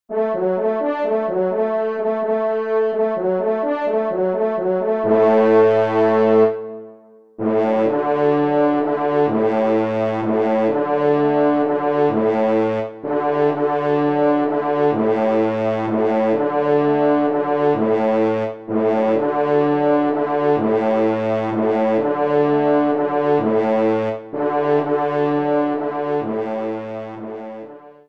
TON SIMPLE :
Pupitre Basse (en exergue)